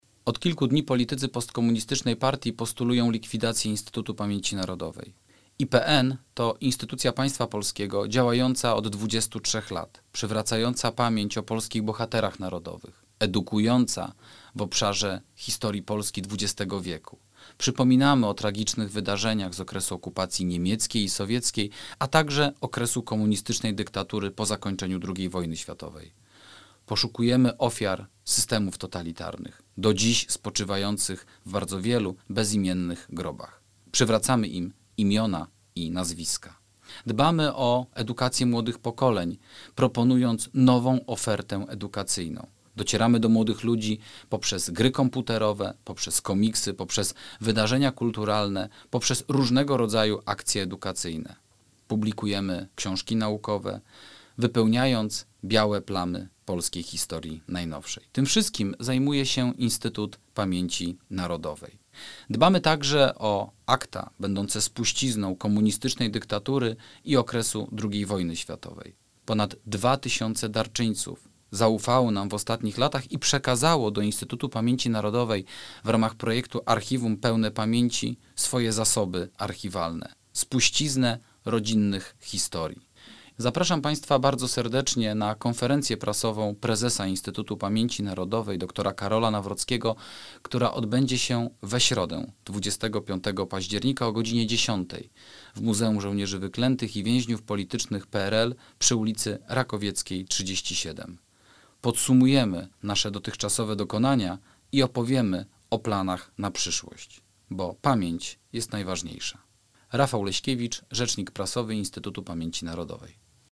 Nie da się zbudować świadomej i bezpiecznej przyszłości bez refleksji historycznej – konferencja prasowa IPN